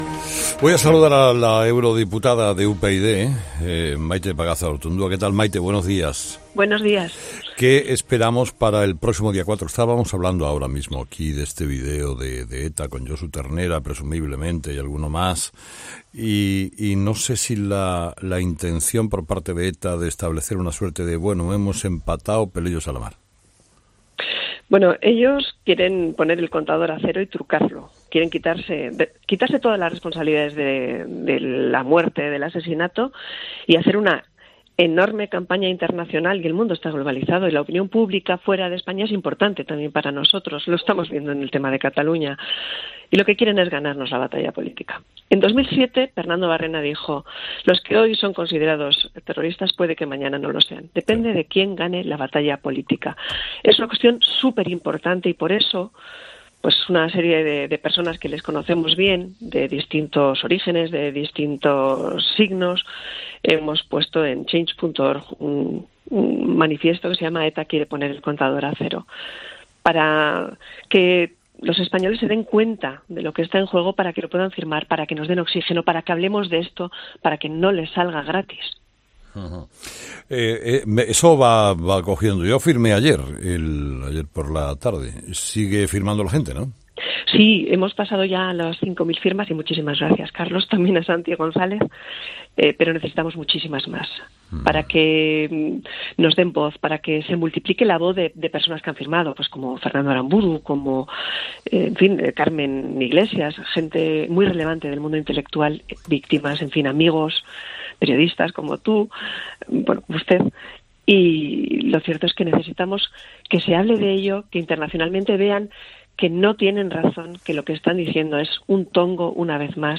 Entrevista a la diputada en el Parlamento Europeo por UYD Maite Pagazaurtundúa